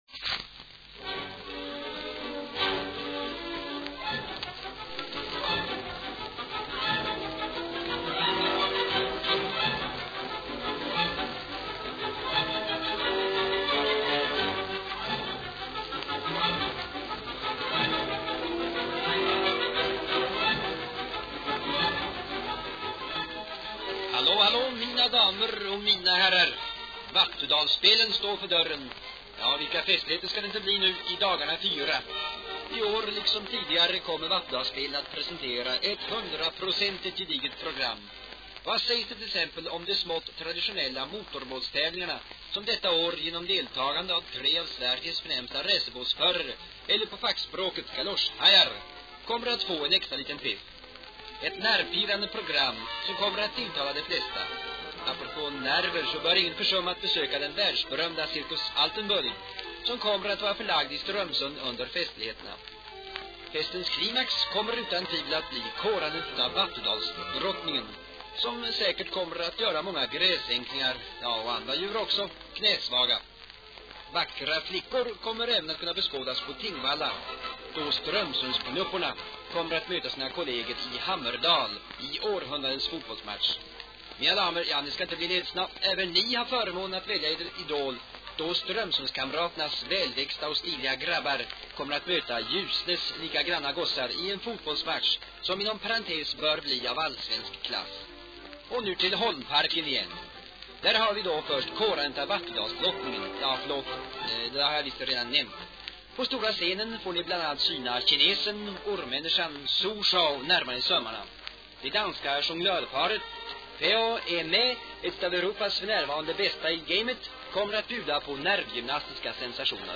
reklamskiva för Vattudalsspelen 1949. Tal av Yngve Gamlin.